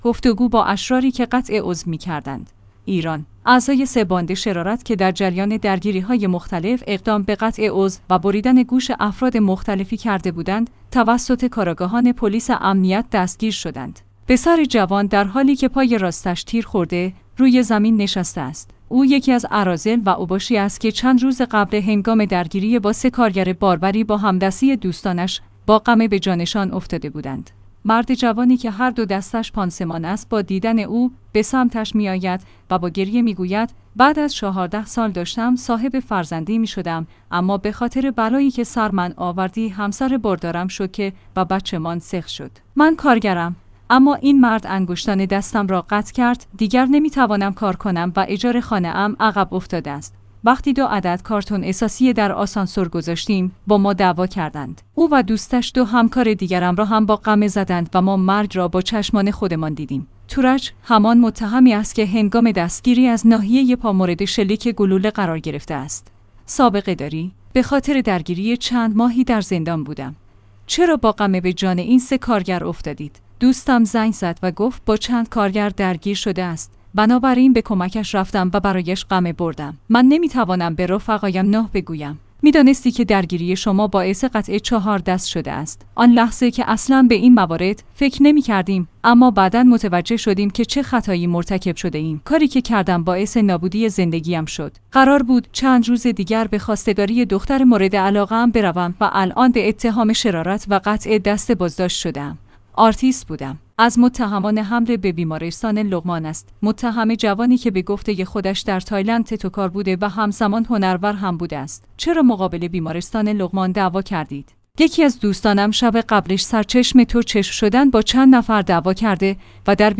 گفتگو با اشراری که قطع عضو می‌کردند!